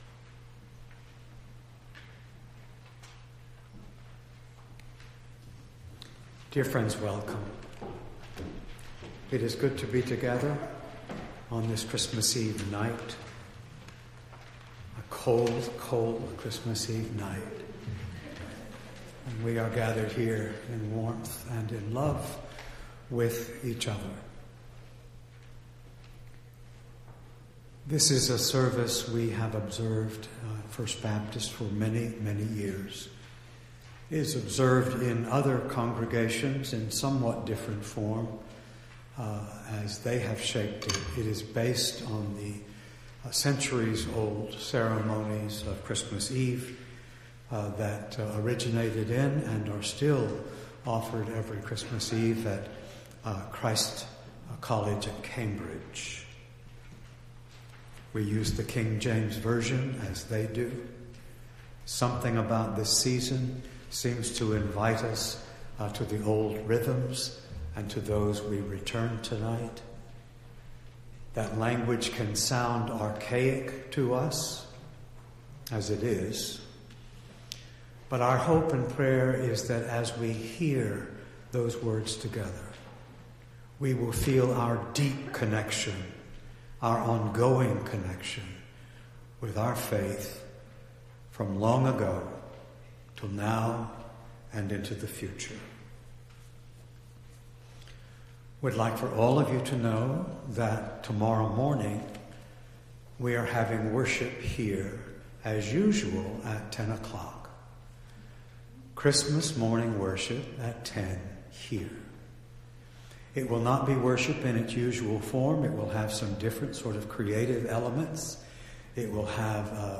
December 24, 2022 – A Festival of Lessons & Carols | First Baptist Church of Ann Arbor
Entire December 24th Service